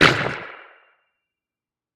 Minecraft Version Minecraft Version snapshot Latest Release | Latest Snapshot snapshot / assets / minecraft / sounds / mob / glow_squid / hurt1.ogg Compare With Compare With Latest Release | Latest Snapshot
hurt1.ogg